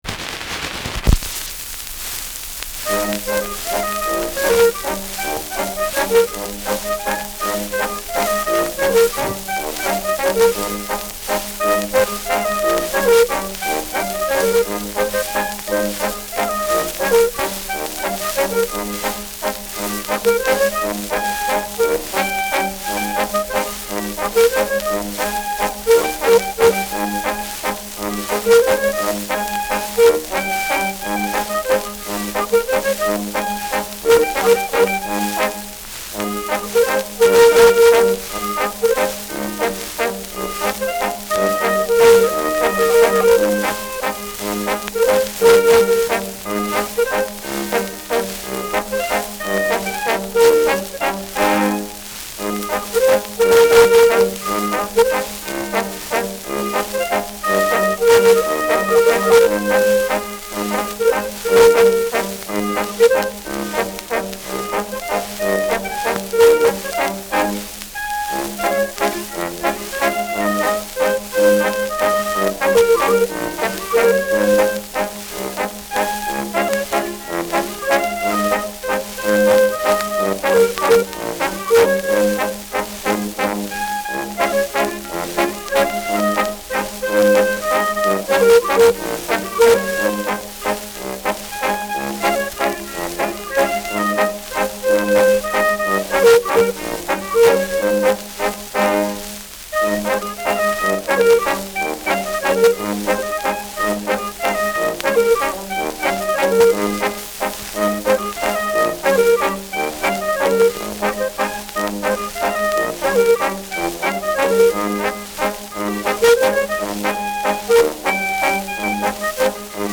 Schellackplatte
Abgespielt : Nadelgeräusch : Teils leicht verzerrt
Boxberger Bauern-Instrumental-Quartett (Interpretation)
Folkloristisches Ensemble* FVS-00015